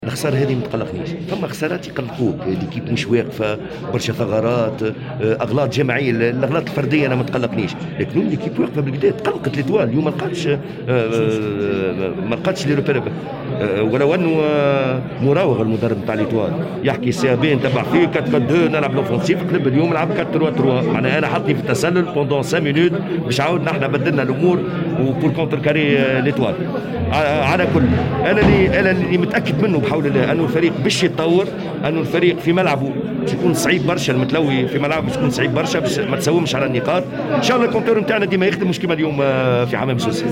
” الهزيمة البارح ضد النجم الساحلي ما تقلقناش” (تصريح)